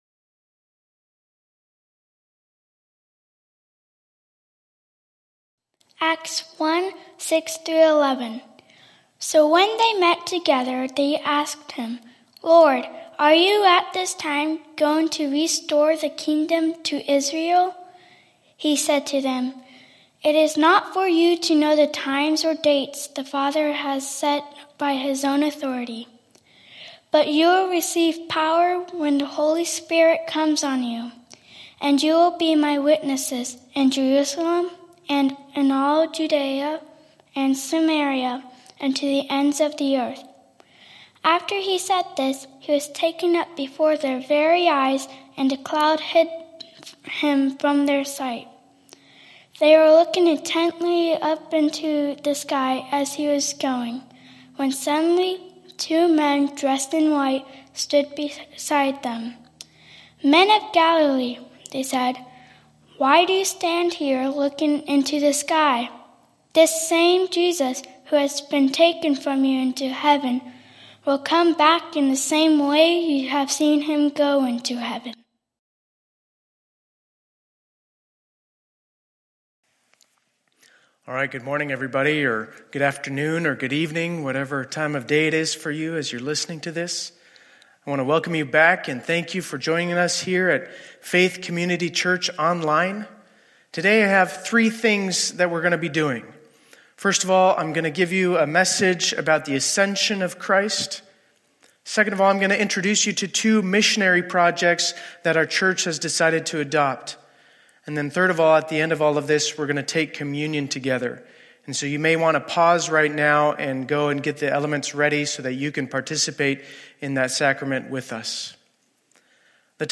Scripture reading, Teaching, Communion, and Lord’s Prayer
Audio of Sermon